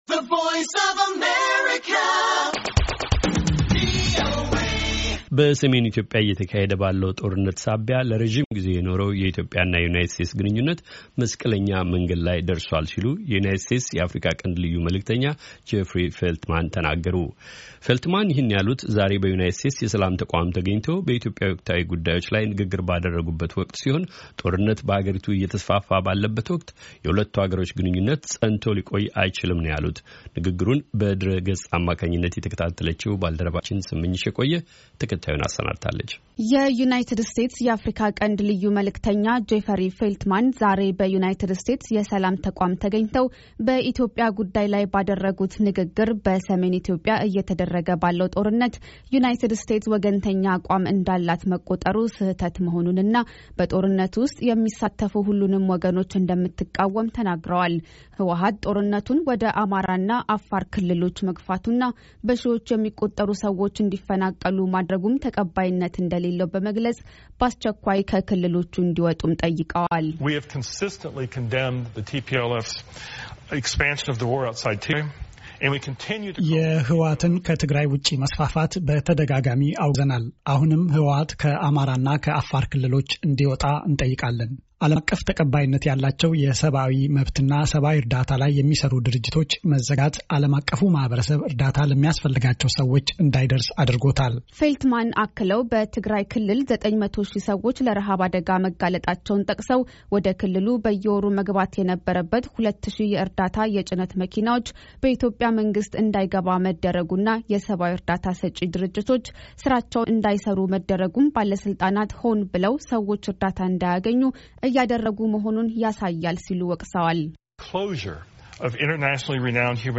በሰሜን ኢትዮጵያ እየተካሄደ ባለው ጦርነት ምክንያት ለረጅም ግዜ የኖረው የኢትዮጵያ እና የዩናይትድ ስቴትስ ግንኙነት መስቀለኛ መንገድ ላይ መድረሱን የዩናይትድ ስቴትስ የአፍሪካ ቀንድ ልዩ መልዕክተኛ ጄፈሪ ፌልትማን አስታውቀዋል። ፌልትማን ይህን ያሉት ዛሬ በዩናይትድ ስቴትስ የሰላም ተቋም ተገኝተው በኢትዮጵያ ወቅታዊ ጉዳይ ላይ ንግግር ባደረጉበት ወቅት ሲሆን ጦርነት በሀገሪቱ እየተስፋፋ ባለበት ወቅቱ የሁለቱ ሀገራት ግኙነት ፀንቶ ሊቆይ እንደማይችል ተናግረዋል።